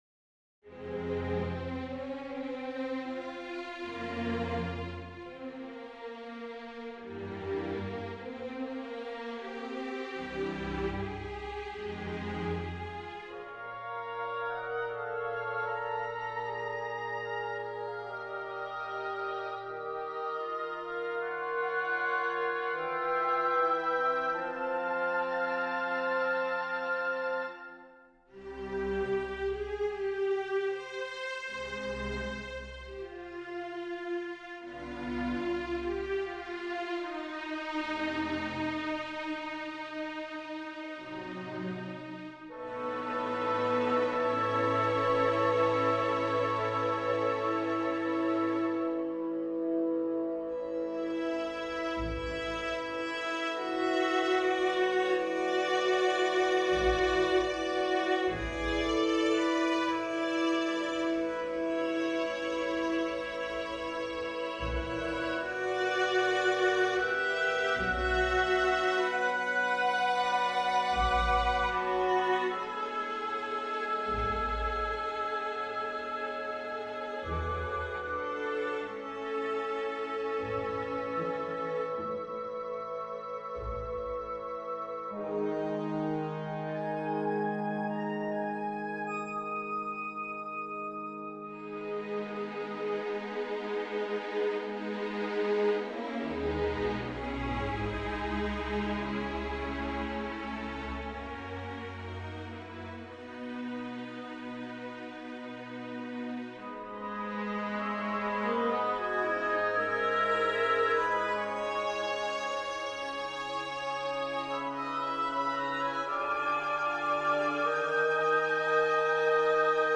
Viola concerto
This was my attempt at a pastiche Romantic viola concerto.